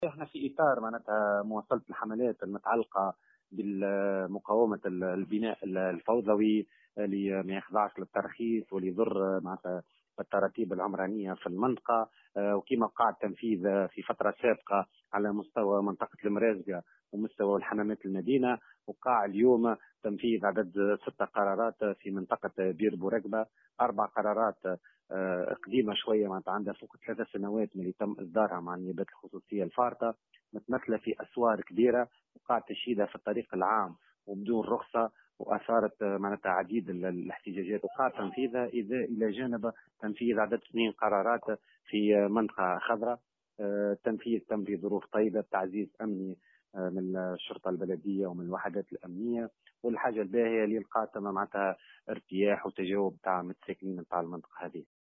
أكد رئيس النيابة الخصوصية و معتمد الحمامات، محمد عبد الواحد في تصريح لمراسلة "الجوهرة أف أم" أنه تم اليوم وفي إطار مقاومة الانتصاب الفوضوي تنفيذ 6 قرارات هدم بمنطقة بئر بورقبة.